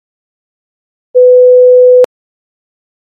(Pour écouter les sons à différentes fréquences ci-dessous, il suffit de cliquer sur celui que vous souhaitez entendre)
500 Hz]